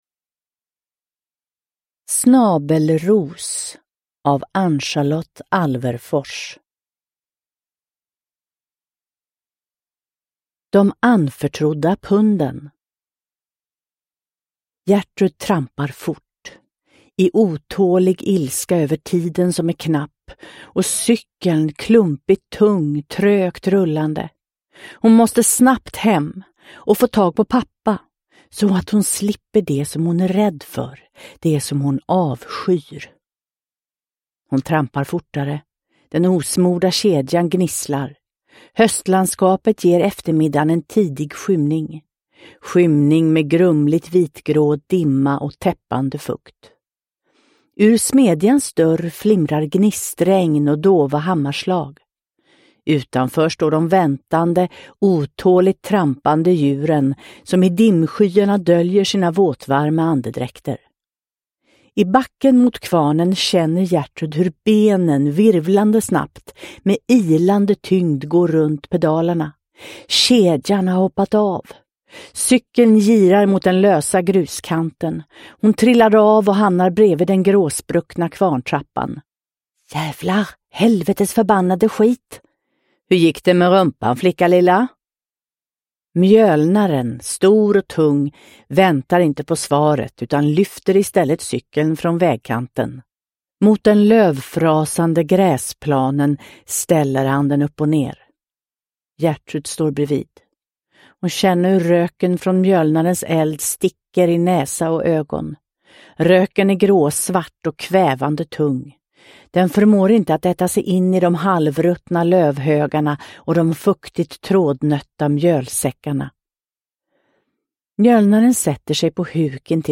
Snabelros – Ljudbok – Laddas ner